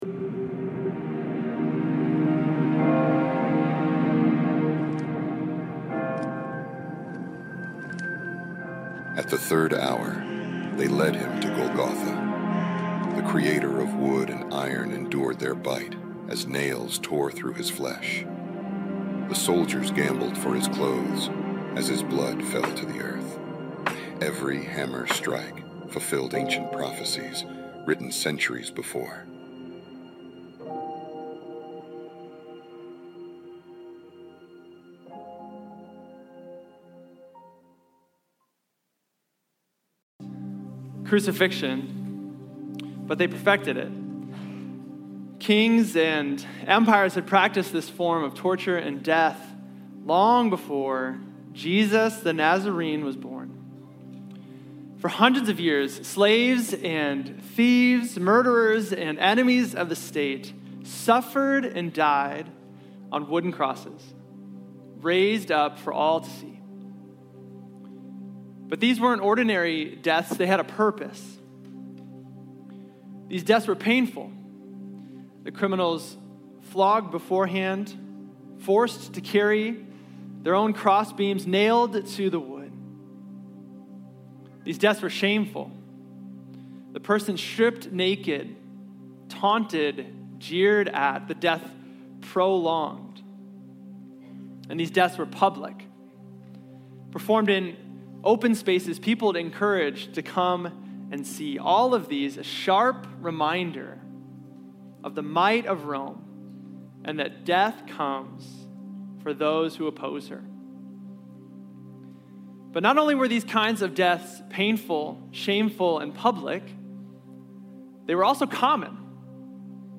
Selected clips of the Good Friday service at Bethel Hobart/Portage.